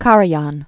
(kärə-yän), Herbert von 1908-1989.